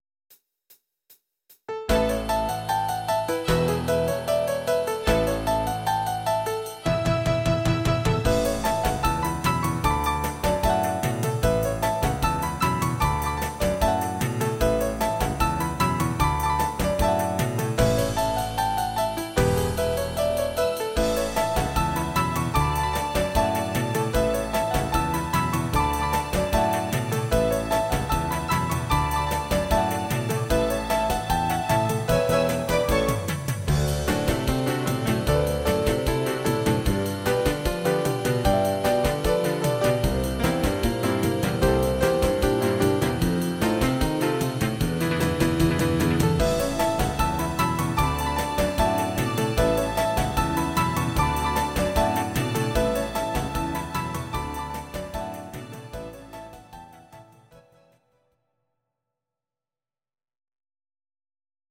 Audio Recordings based on Midi-files
Instrumental, 1960s